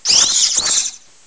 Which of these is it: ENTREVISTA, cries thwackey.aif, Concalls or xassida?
cries thwackey.aif